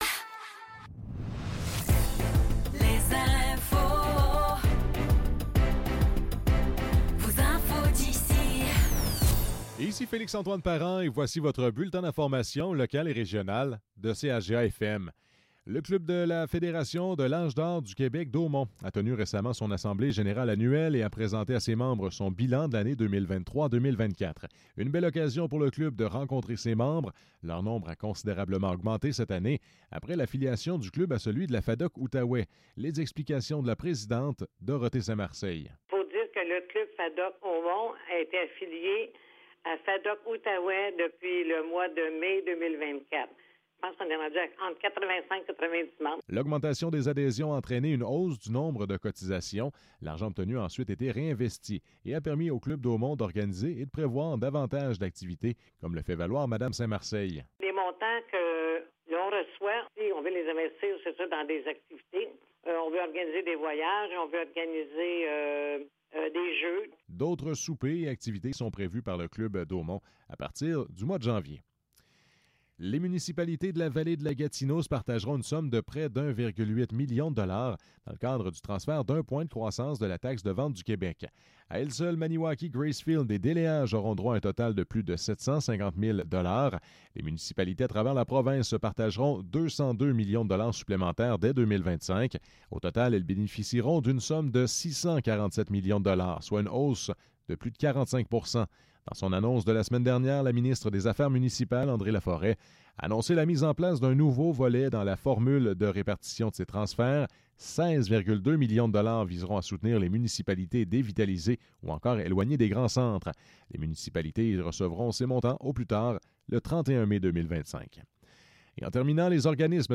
Nouvelles locales - 19 novembre 2024 - 15 h